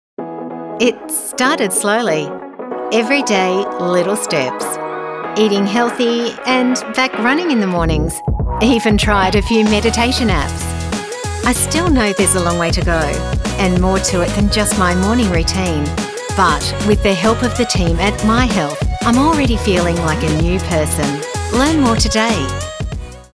Female
Yng Adult (18-29), Adult (30-50)
Friendly, professional, calm, corporate, energetic, authentic, relaxed, natural, engaging - a versatile voice for all projects!
Radio Commercials
Natural Speak
All our voice actors have professional broadcast quality recording studios.